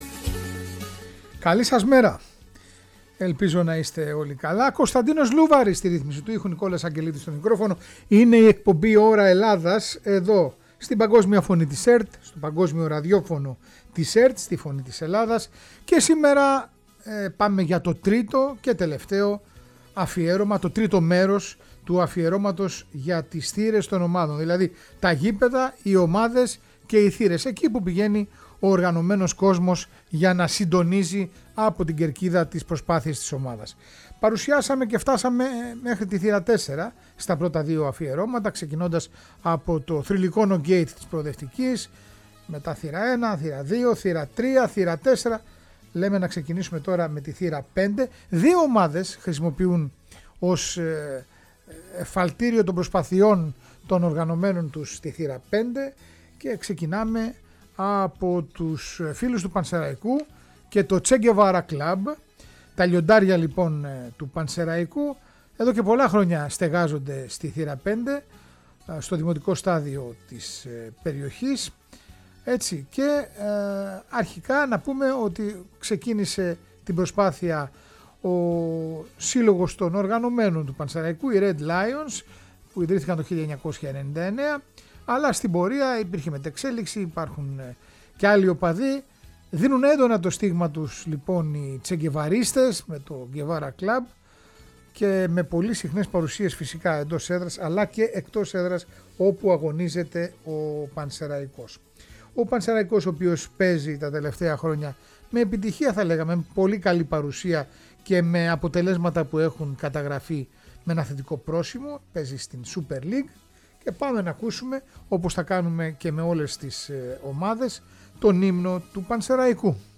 Ένα μοναδικό οδοιπορικό σε κάθε μια ομάδα που έχει αριθμημένη θύρα – σημείο αναφοράς στο γήπεδο της. Παράλληλα ψηλαφίζοντας τα κιτάπια της ιστορίας ανακαλύπτουμε και σας παρουσιάζουμε και τους πρώτους ιστορικά ύμνους των ομάδων…